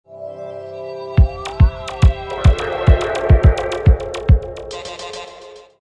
Dance - Electro